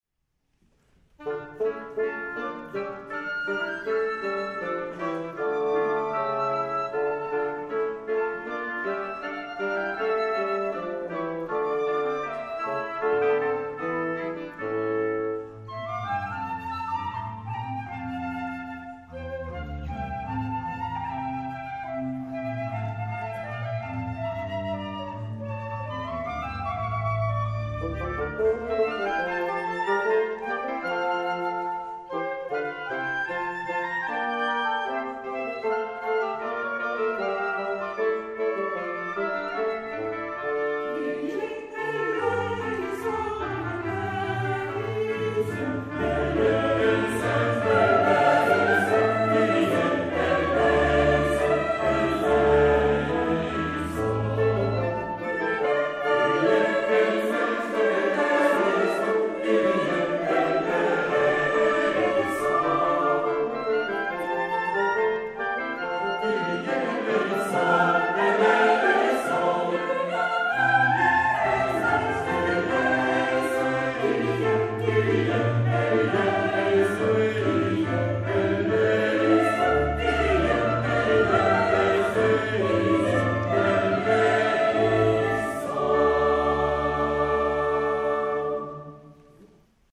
Quelques extraits de concerts des dernières années
Eglise Sainte Jeanne d’Arc, rue Salengro à Tours
La célèbre « Messe de minuit » de Charpentier et « Laudes à la Nativité » de Respighi avec orchestre « champêtre ».